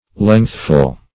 lengthful - definition of lengthful - synonyms, pronunciation, spelling from Free Dictionary
lengthful - definition of lengthful - synonyms, pronunciation, spelling from Free Dictionary Search Result for " lengthful" : The Collaborative International Dictionary of English v.0.48: Lengthful \Length"ful\ (-f[.u]l), a. Long.